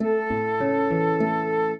flute-harp
minuet7-8.wav